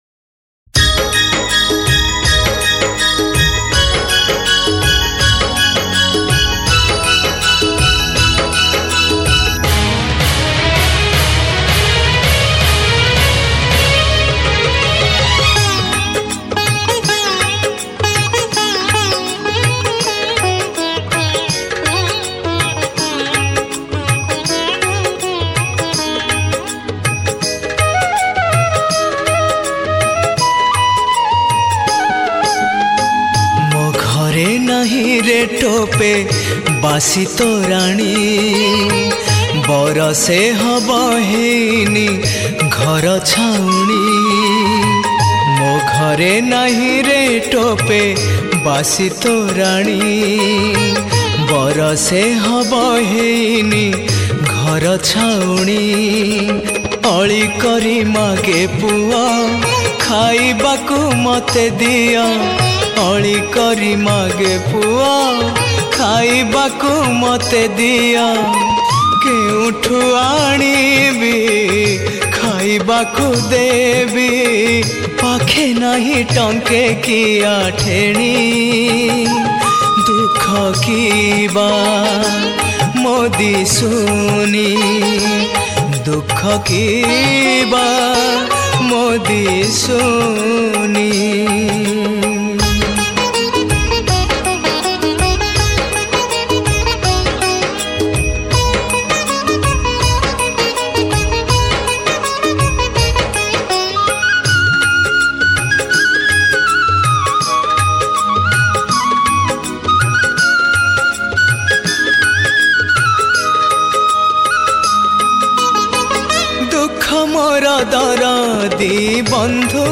Odia Bhajan Sad Song